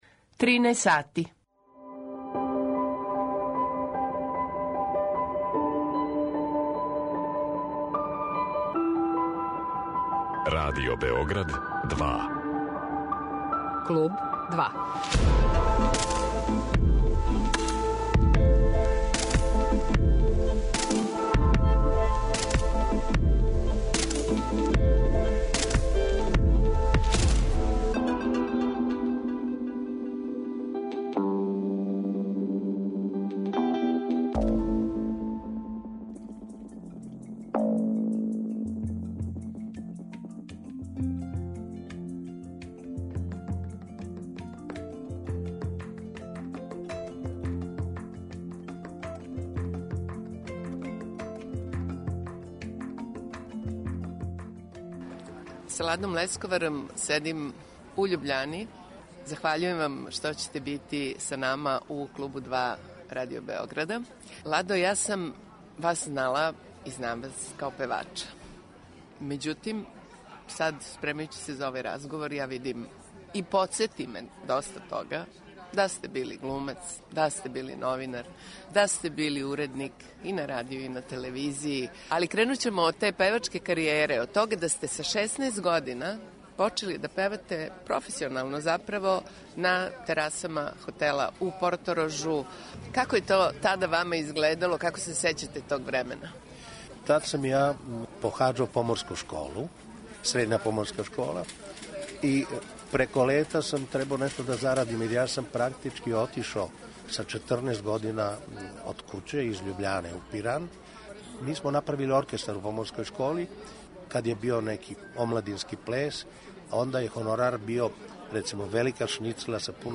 Пева и данас, непромењеним гласом. У Клубу 2 нам приповеда свој више него занимљив живот.